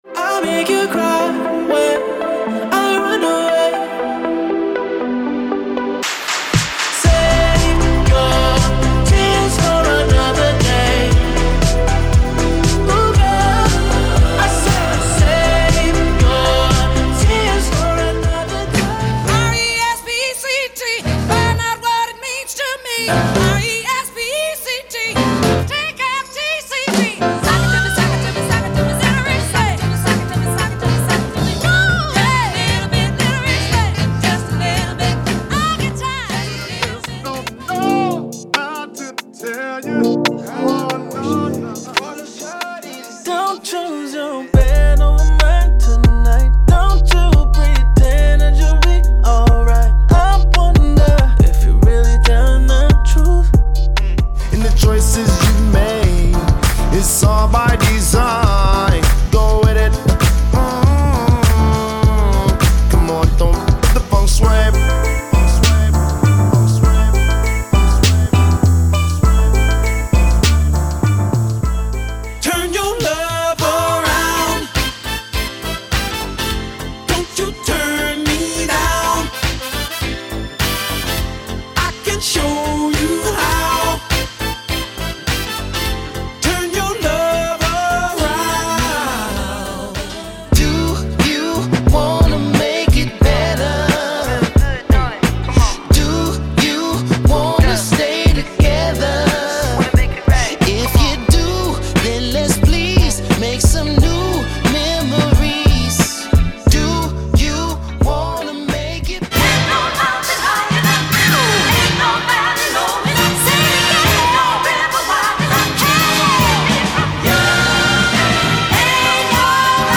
Description: A celebration of Black Music.